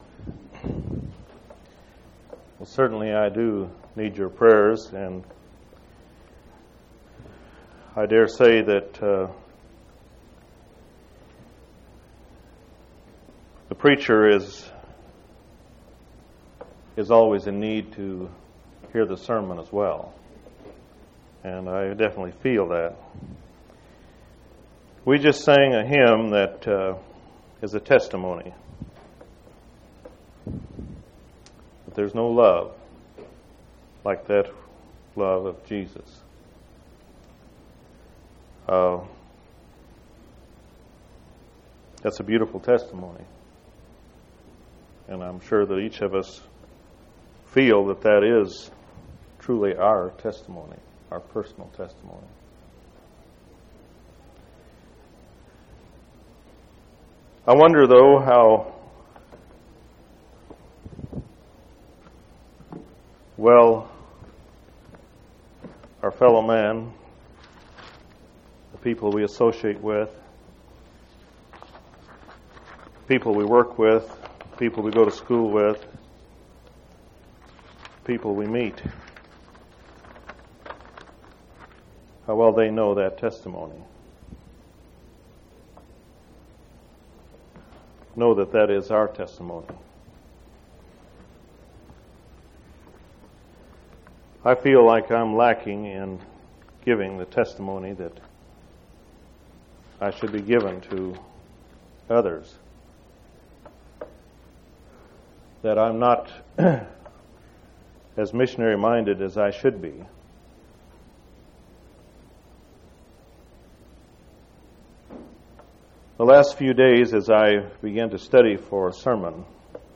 7/1/1990 Location: East Independence Local Event